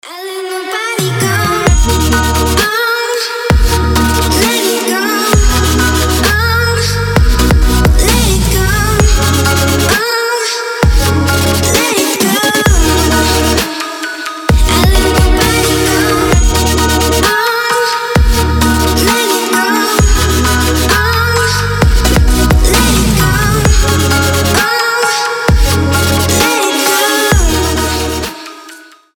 • Качество: 320, Stereo
женский голос
Electronic
Trap
чувственные
future bass